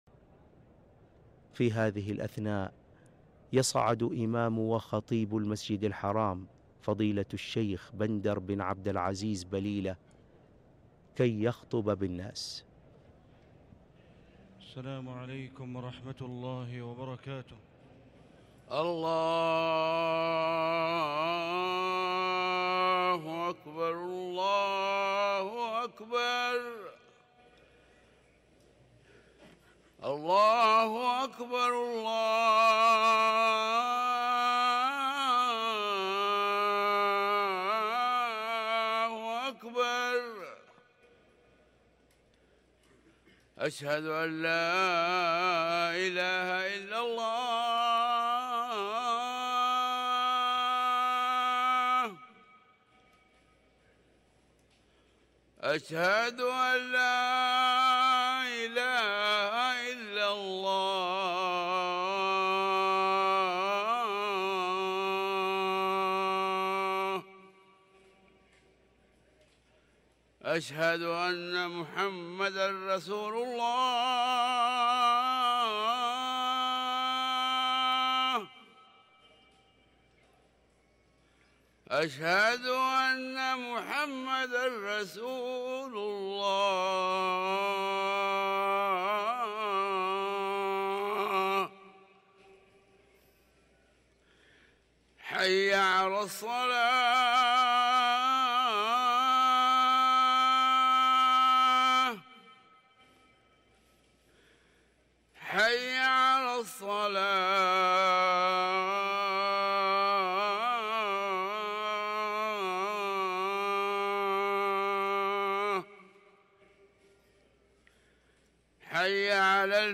ركن الأذان